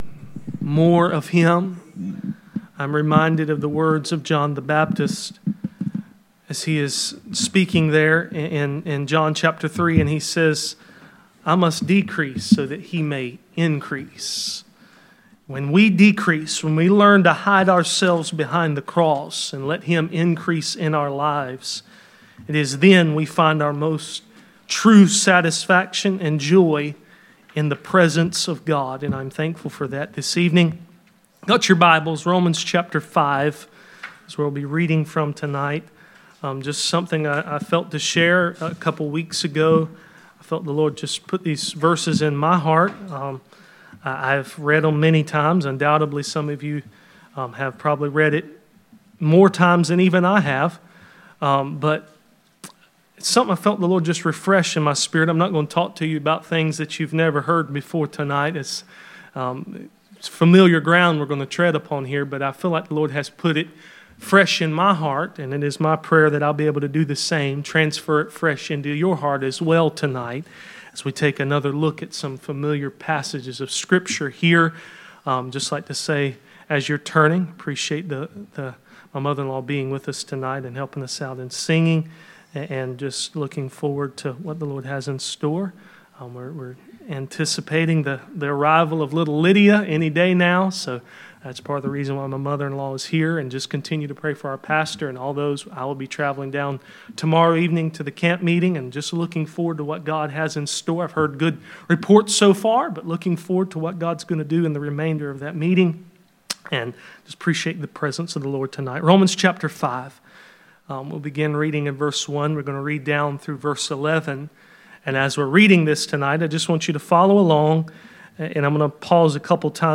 None Passage: Romans 5:1-11 Service Type: Midweek Meeting %todo_render% « Be care how you listen